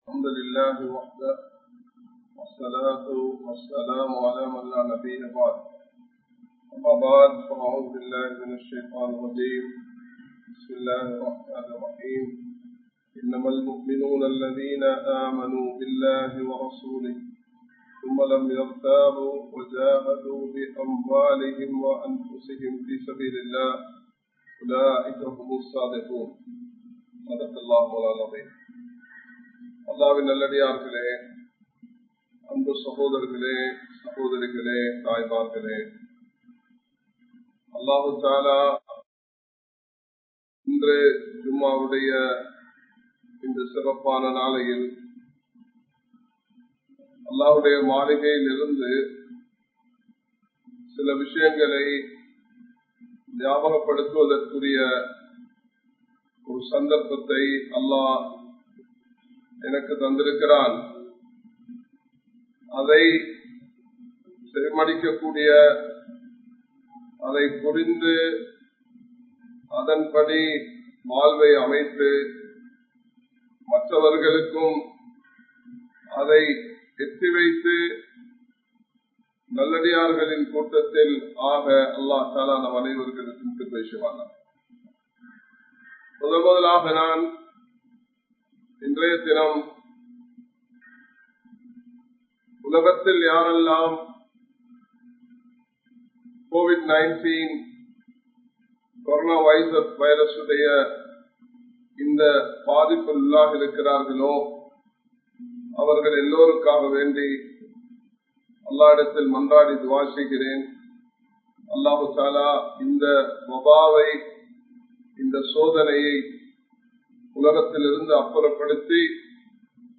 Indraya Soolalil Muslimkal Nadanthukolla Veandiya Muraihal (இன்றைய சூழலில் முஸ்லிம்கள் நடந்து கொள்ள வேண்டிய முறைகள்) | Audio Bayans | All Ceylon Muslim Youth Community | Addalaichenai
Live Stream